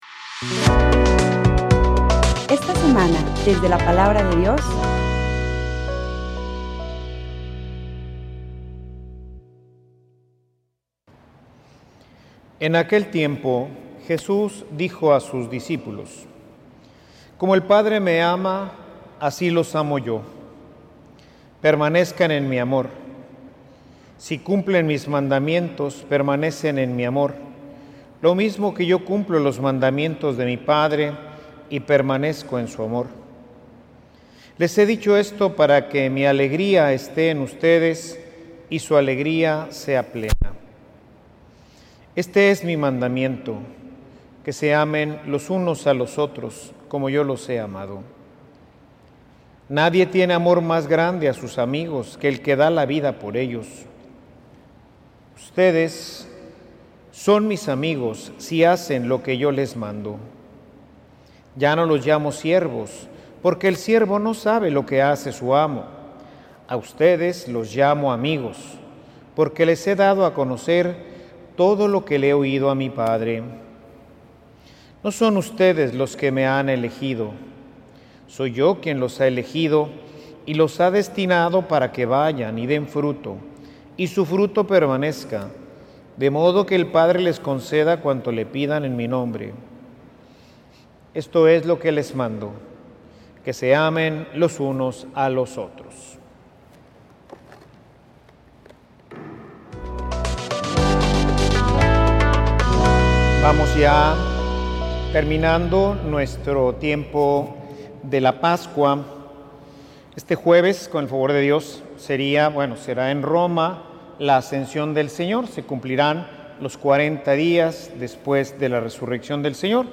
Homilia_Ser_realmente_amigo_de_Jesus.mp3